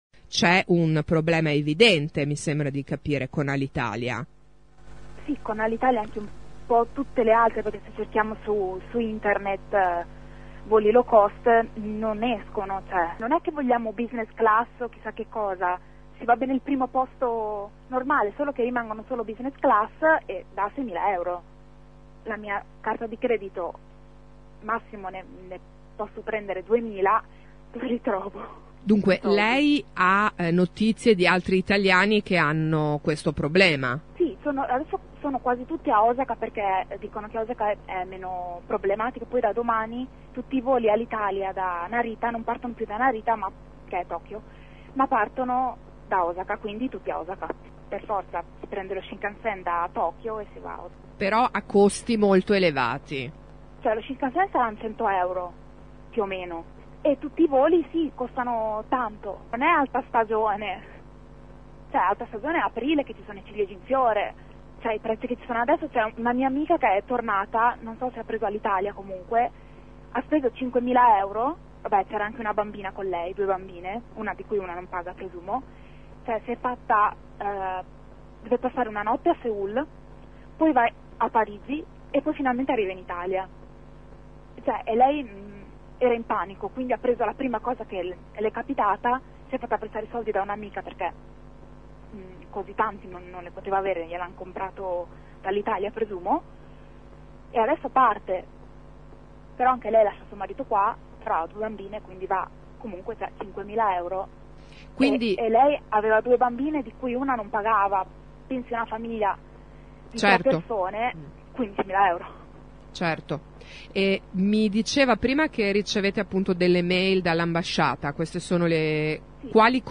Un gruppo di cittadini italiani in Giappone sta facendo girare su Facebook un appello per segnalare la difficoltà a rientrare in Italia per gli alti costi del biglietto praticati da Alitalia e da altre compagnie. Ascolta L’intervista.